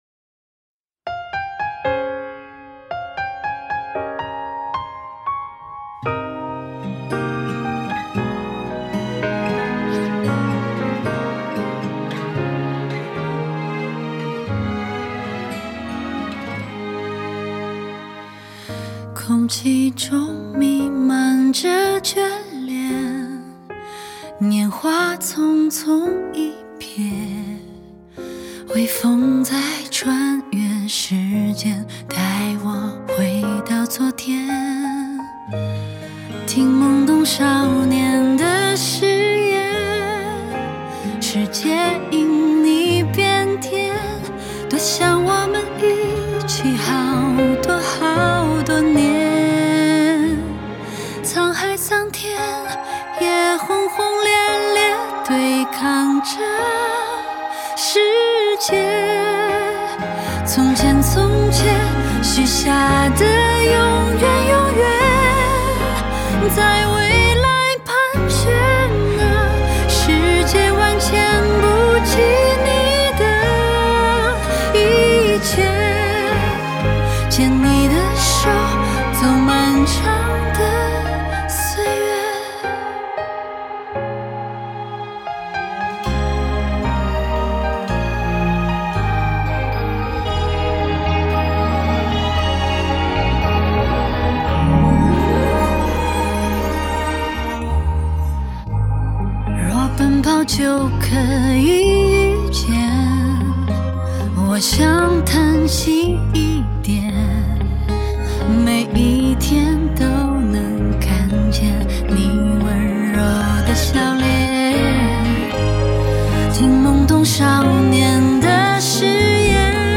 4/4 60以下